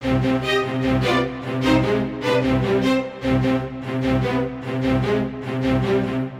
描述：一小包史诗般的弦乐和低音。
Tag: 140 bpm Cinematic Loops Strings Loops 2.15 MB wav Key : C